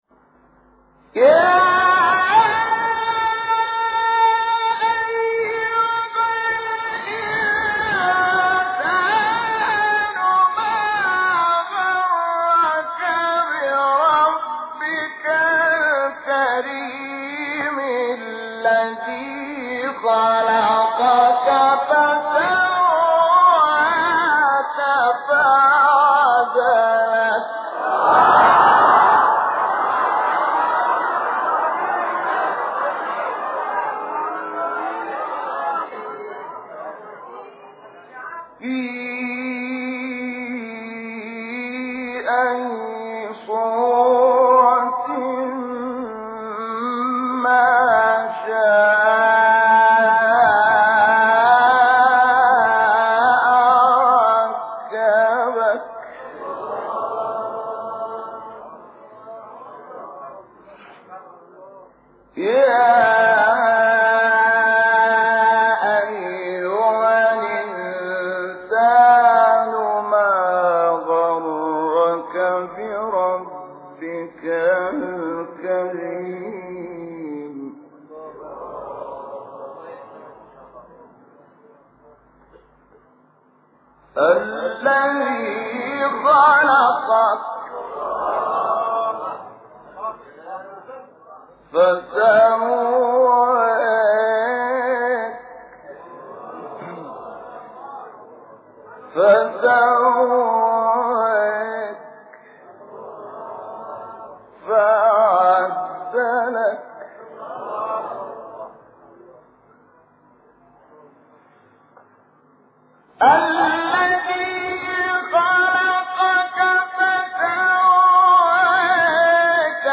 قطعات شنیدنی از تلاوت سوره مبارکه انفطار را با صوت قاریان محمد اللیثی، شحات محمد انور، عبدالباسط محمد عبدالصمد، محمد صدیق منشاوی و راغب مصطفی غلوش می‌شنوید.
آیات ۶ تا ۸ سوره انفطار با صوت شحات محمد انور